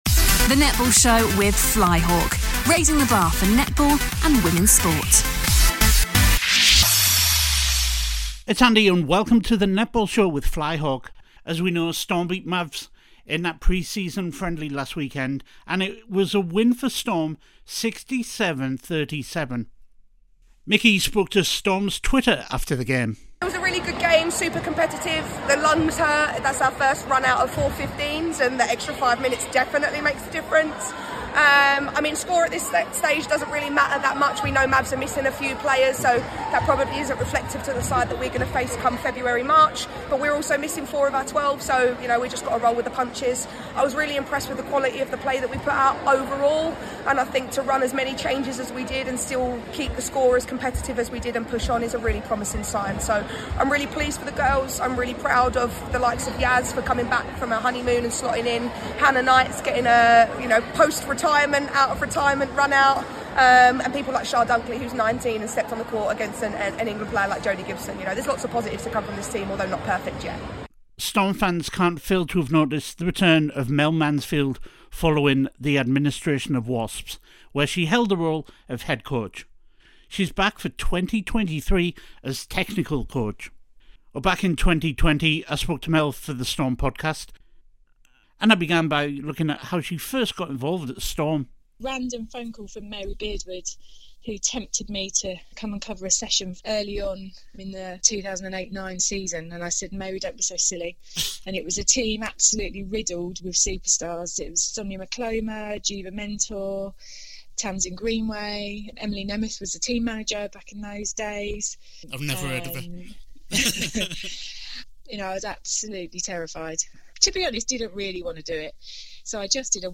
archive interview